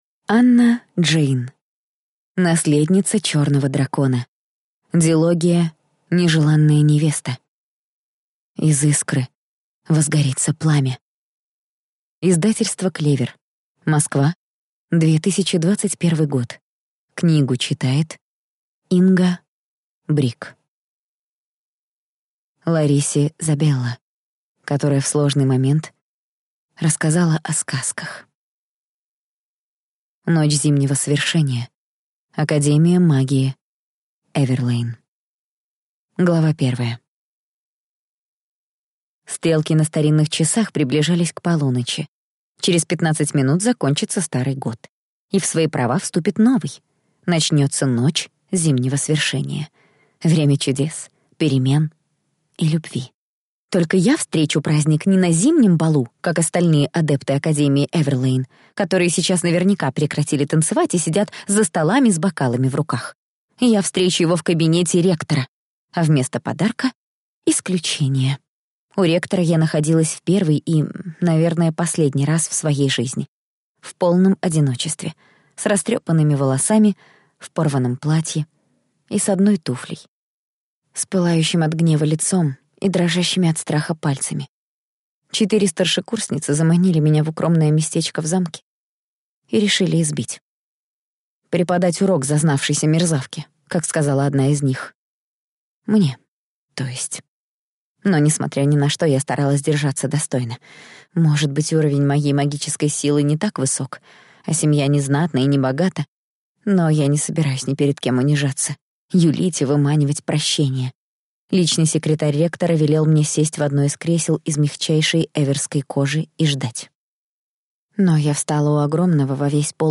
Аудиокнига Наследница черного дракона | Библиотека аудиокниг